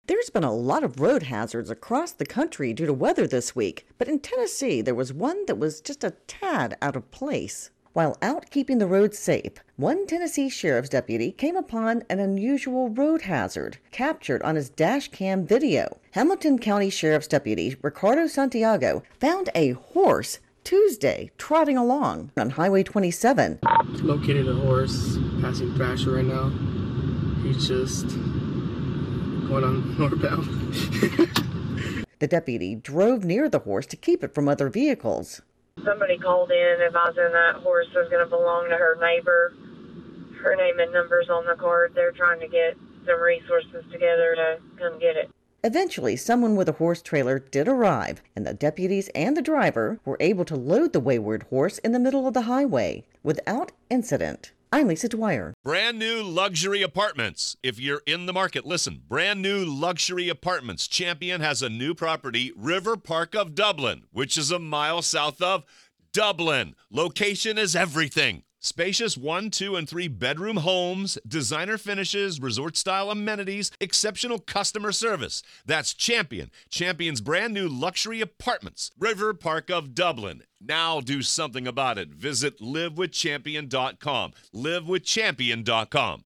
SOUND COURTESY: HAMILTON COUNTY SHERIFF'S OFFICE